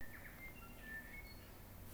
New Zealand bellbird chirping Direct link to audio file
This one was beautiful and made a lot more sense once I learned the name: it was reminiscent of hearing the church bells go off across a town or a city. I never got to see it, but its sound continued to bounce around the valley every few minutes throughout the afternoon.
new_zealand_bellbird.wav